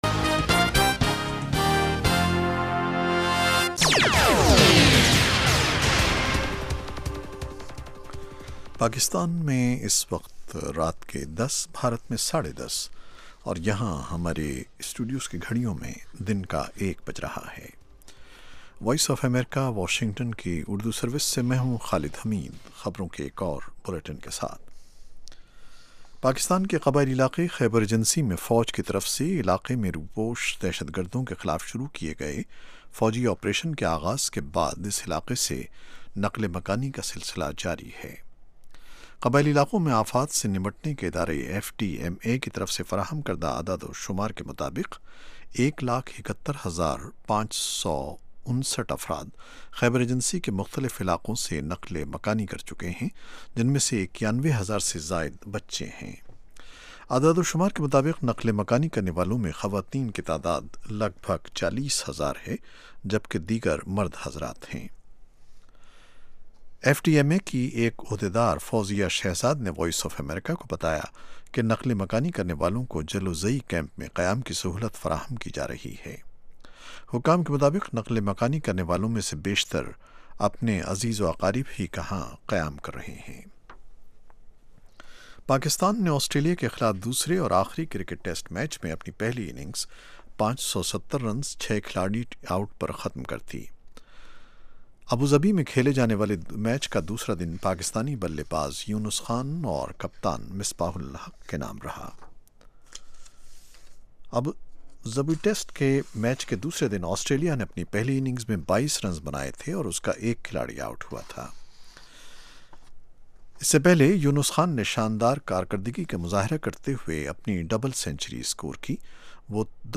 The URL has been copied to your clipboard No media source currently available 0:00 0:59:59 0:00 Direct link | ایم پی تھری اس پروگرام میں تجزیہ کار اور ماہرین سیاسی، معاشی، سماجی، ثقافتی، ادبی اور دوسرے موضوعات پر تفصیل سے روشنی ڈالتے ہیں۔